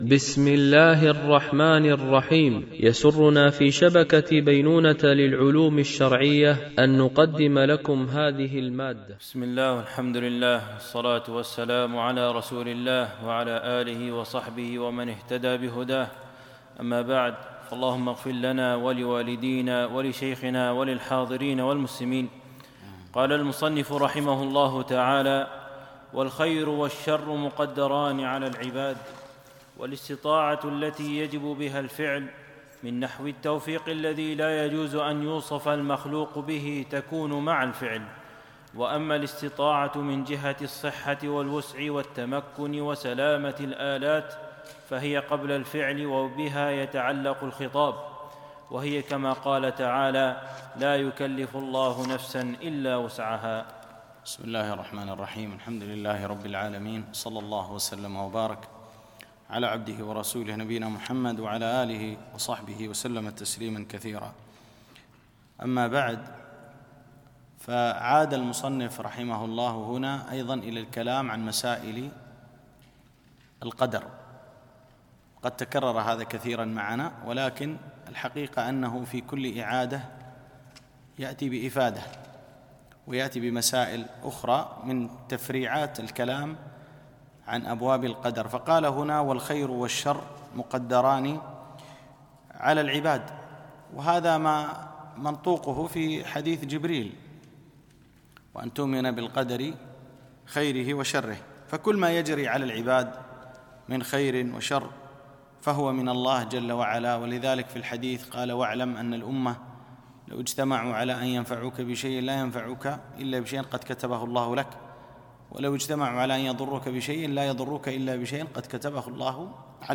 مباحث إيمانية - الدرس 13